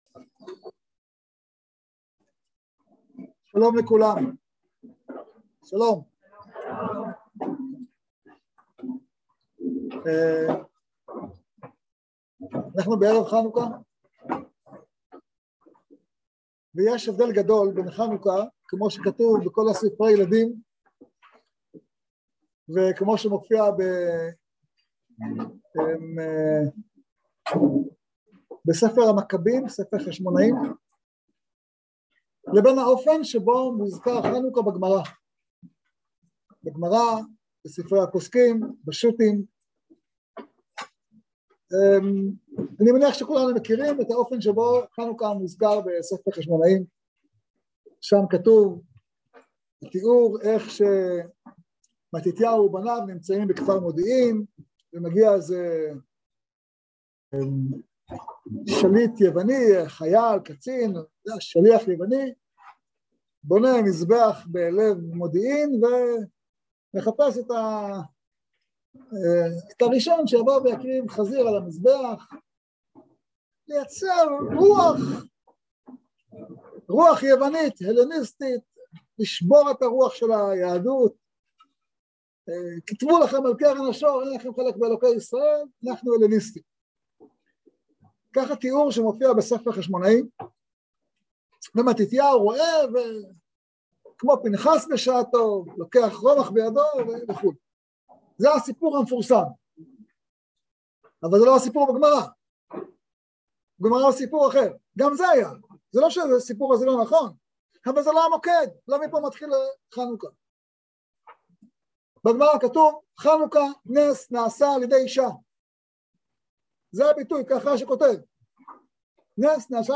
בין חושך לאור - על תרבות ישראלית מול טומאת יוון | יום עיון לחנוכה תשפ"ב | מדרשת בינת